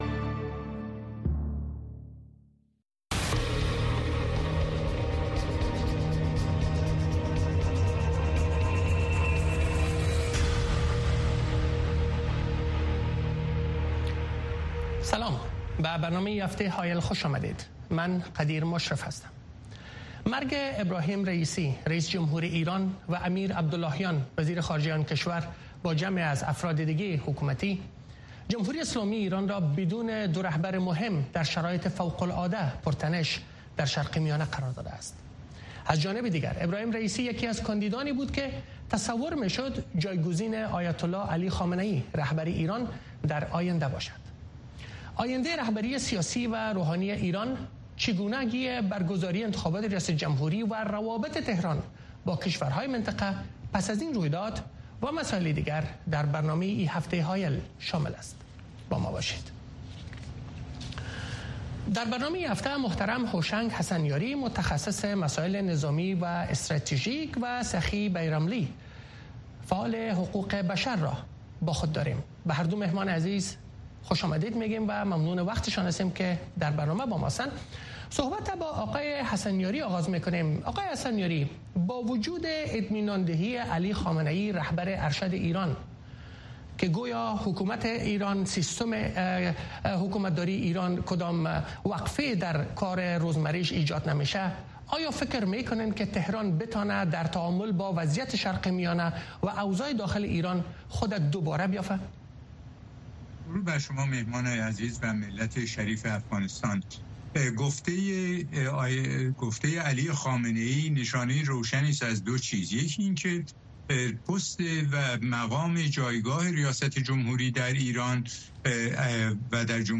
په دغه خپرونه کې د بېلابېلو اړخونو سره په مخامخ، ژورو او تودو بحثونو کې د افغانستان، سیمې، او نړۍ مهم سیاسي، امنیتي، اقتصادي، او ټولنیز موضوعات څېړل کېږي.